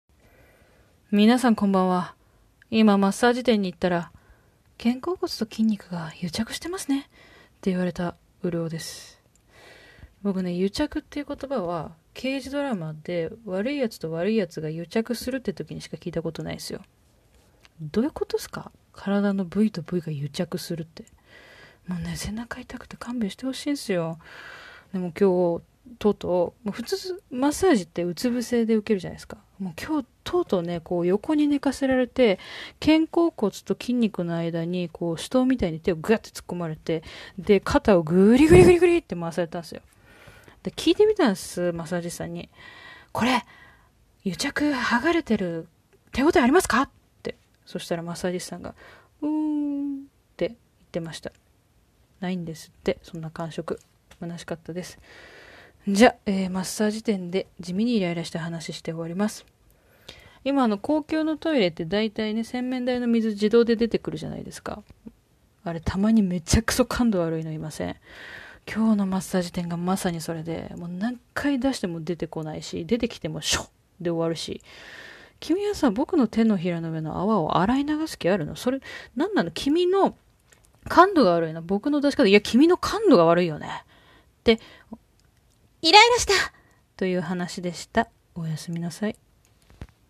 フリートーク #5 地味にイライラした話